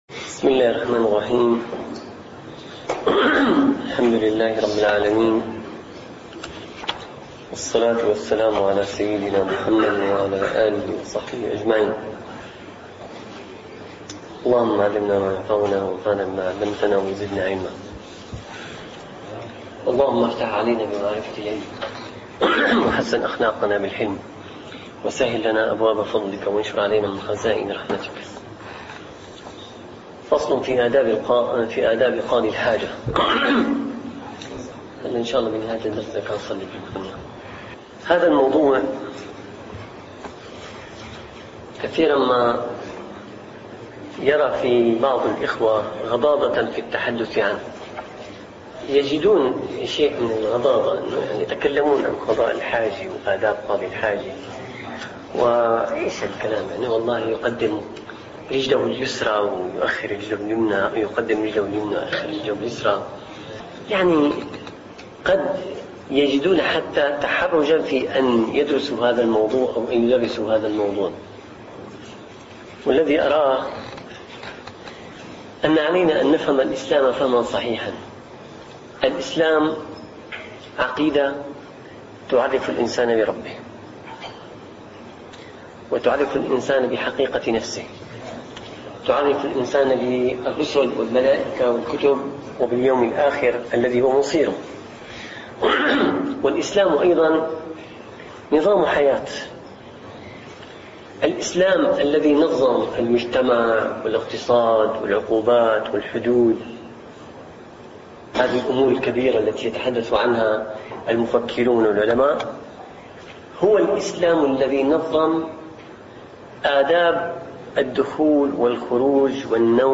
- الدروس العلمية - الفقه الشافعي - المنهاج القويم شرح المقدمة الحضرمية - فصل في آداب قاضي الحاجة.